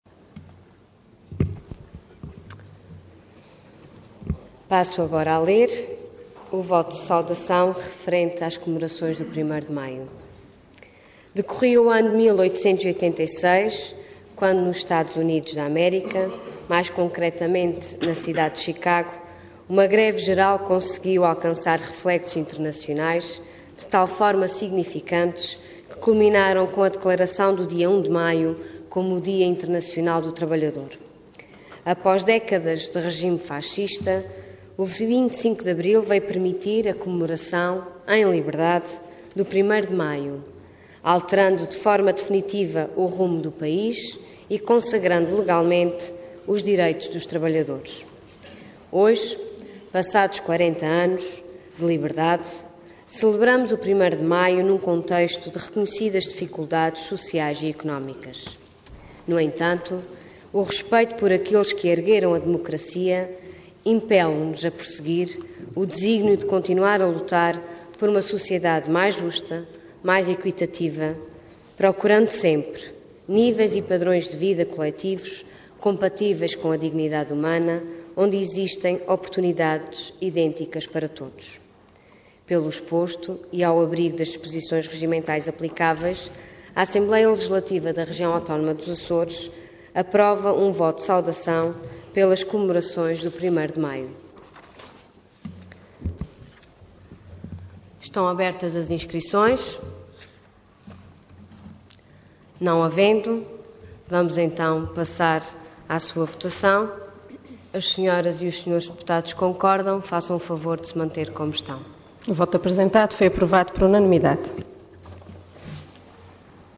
Detalhe de vídeo 8 de maio de 2014 Download áudio Download vídeo Processo X Legislatura Comemorações do 1.º de Maio Intervenção Voto de Saudação Orador Ana Luísa Luís Cargo Presidente da Assembleia Regional Entidade Voto Conjunto